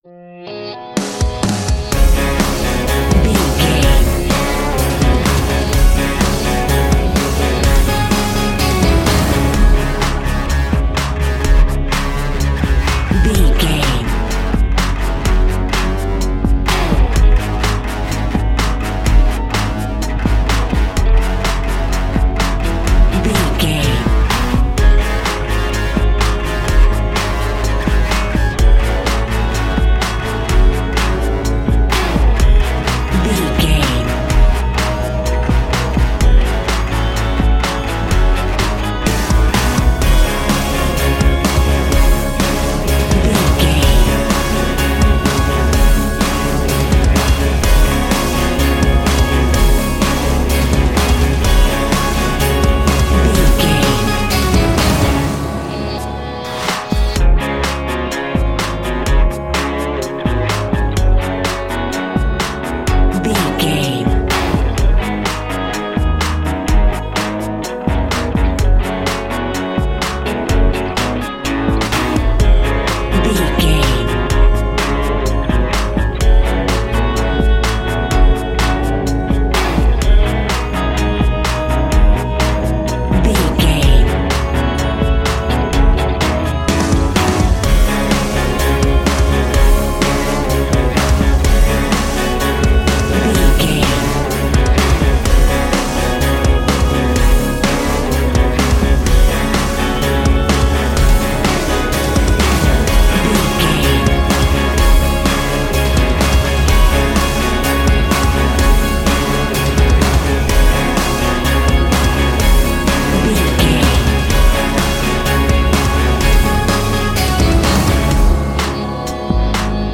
Epic / Action
In-crescendo
Phrygian
C#
drums
electric guitar
bass guitar
hard rock
aggressive
energetic
intense
nu metal
alternative metal